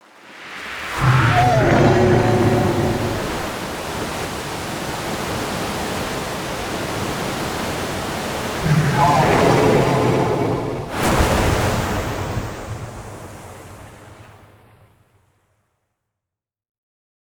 dragon ult uncut.ogg